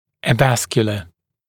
[ə’væskjələ] [eɪ-][э’вэскйэлэ] [eɪ-]аваскулярный; бессосудистый, лишенный сосудов